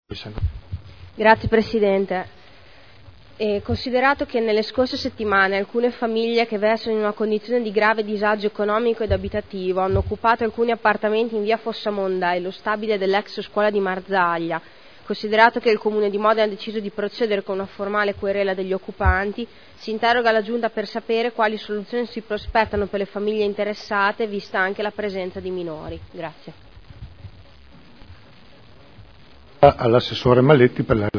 Giulia Morini — Sito Audio Consiglio Comunale